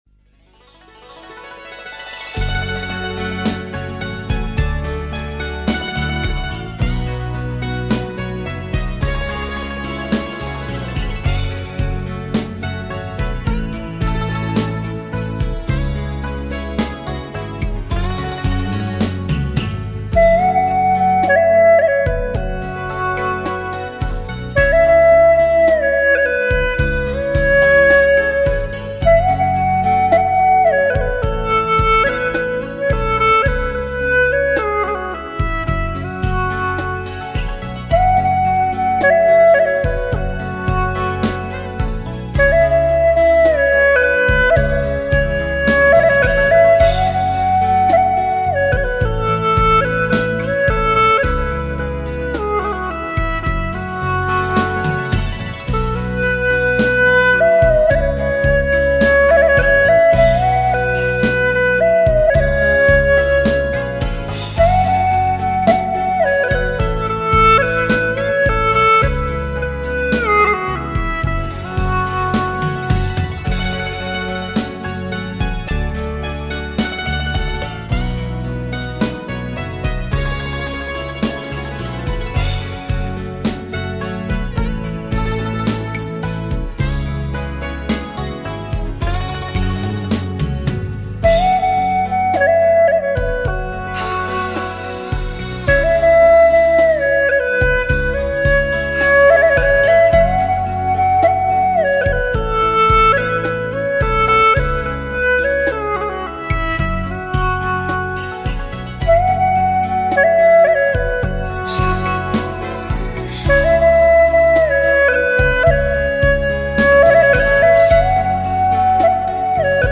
曲类 : 流行
【A调】